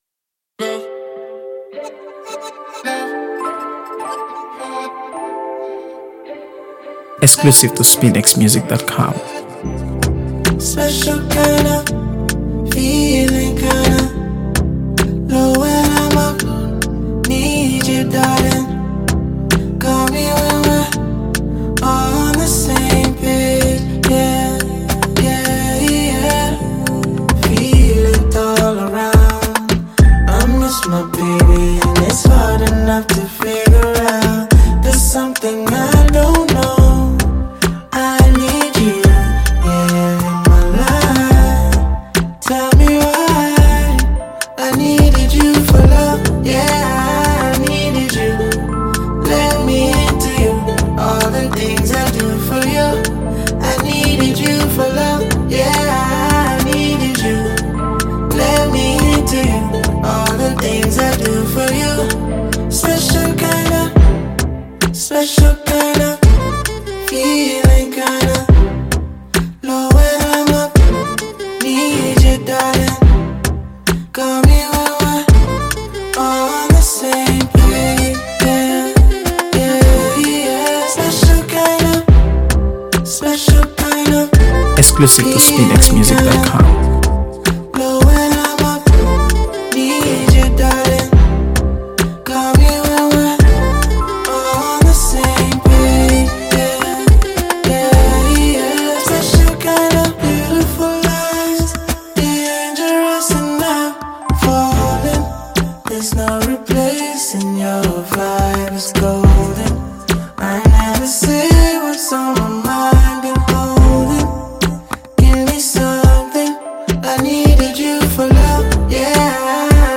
AfroBeats | AfroBeats songs
smooth melodies and heartfelt lyrics